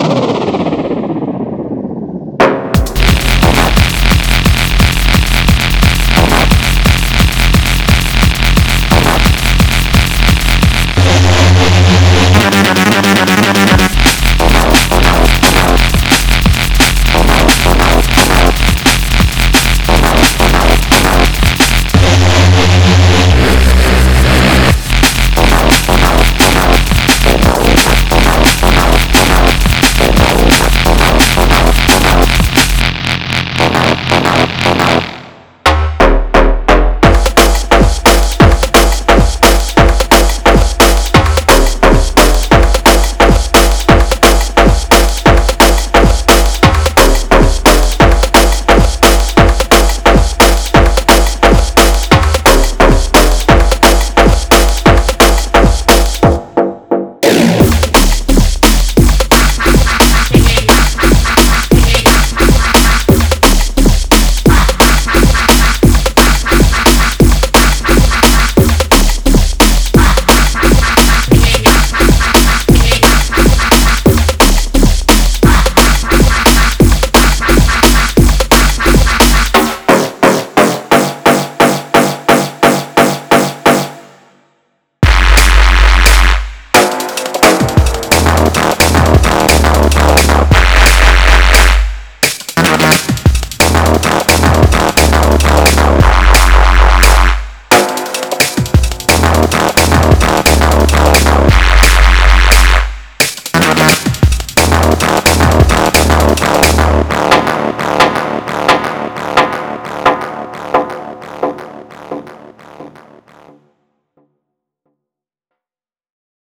This exceptional sample pack features 350 meticulously crafted drum loops and individual drum samples, providing everything you need to build professional, razor-sharp Drum’n’Bass tracks.
Included are complete drum loops and individual components such as kicks, snares, hi-hats, and cymbals, giving producers full flexibility to mix, layer, and create custom drum patterns. Each element is expertly EQ’d for optimal clarity, making it easy to blend sounds seamlessly and experiment with endless combinations.